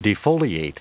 Prononciation du mot defoliate en anglais (fichier audio)
Prononciation du mot : defoliate